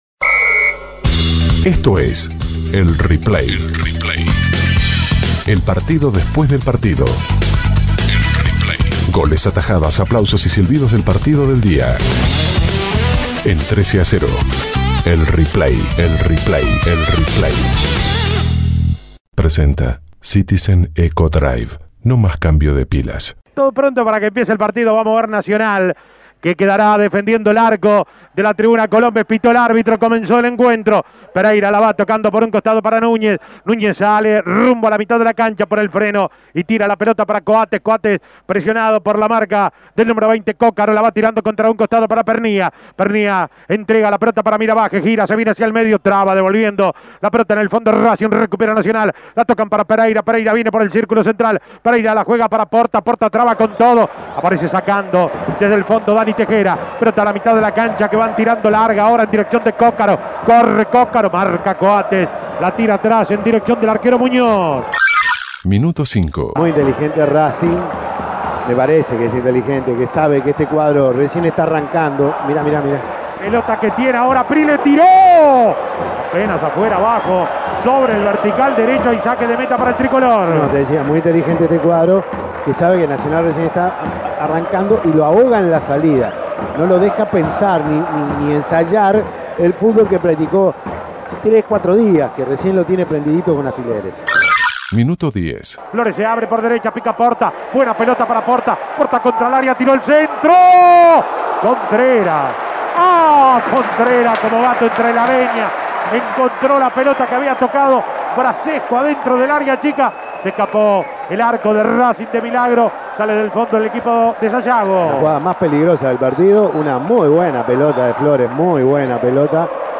Goles y comentarios Escuche el replay de Racing - Nacional Imprimir A- A A+ Nacional empató 2 a 2 con Racing en el estadio Centenario.